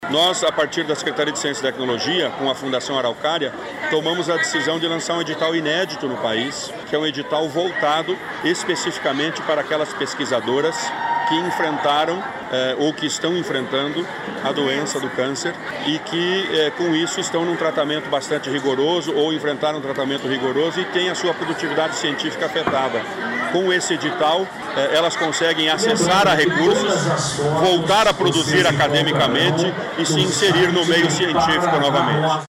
Sonora do secretário de Ciência, Tecnologia e Ensino Superior, Aldo Bona, sobre o edital que incentiva a produção de pesquisadoras diagnosticadas com câncer de mama